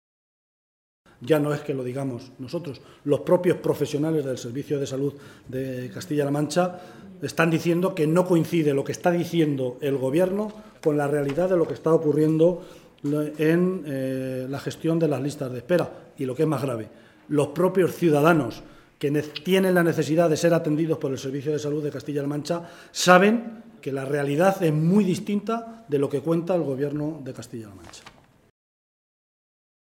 Unos minutos después de dejar registrada esa iniciativa, era el propio portavoz socialista en la Cámara autonómica, José Luis Martínez Guijarro, el que comparecía ante los medios de comunicación para explicar los motivos que habían llevado a los socialistas a exigir esta comisión de investigación y los objetivos que persigue la misma.